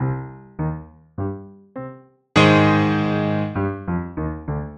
Play Sneaky Piano - SoundBoardGuy
Play, download and share sneaky piano original sound button!!!!
sneaky-piano.mp3